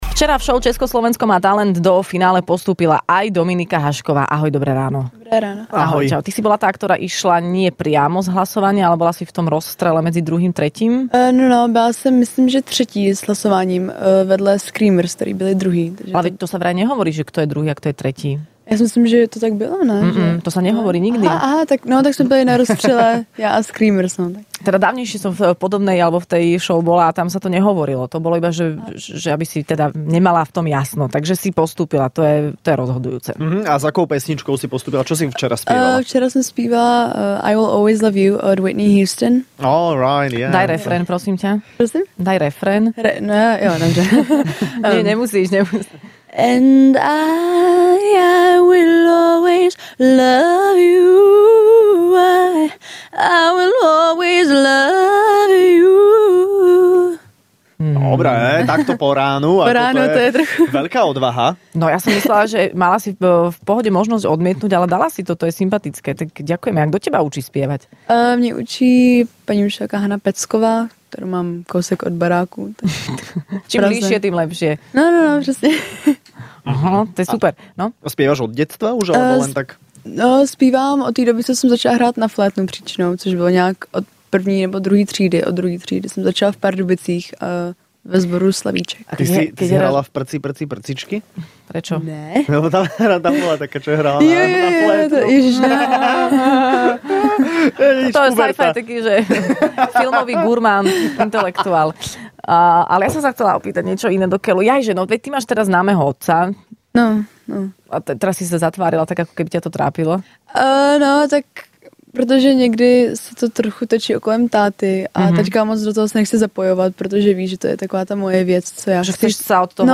naživo zaspievala a hovorila, kto ju učí spievať...